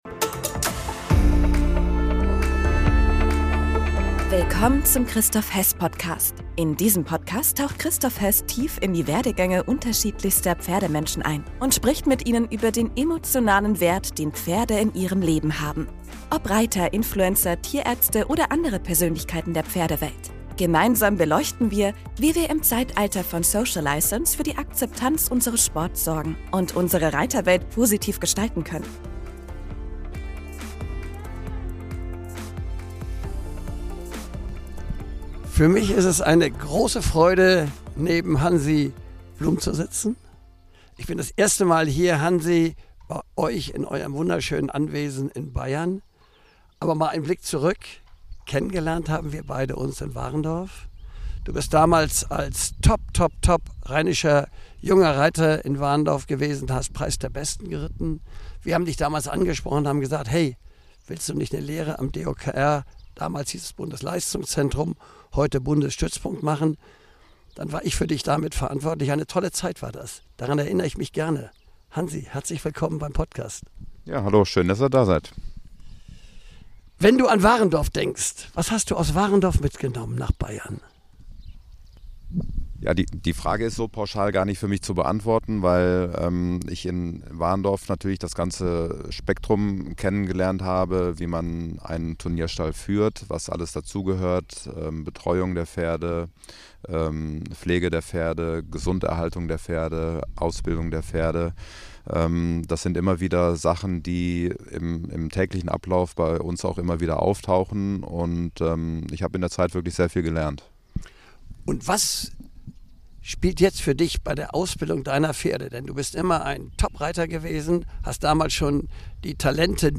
Ein Gespräch über Qualität, Verantwortung, Horsemanship – und die Liebe zum Detail im Spitzensport.